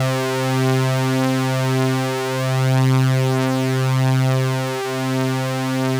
C4_trance_lead_1.wav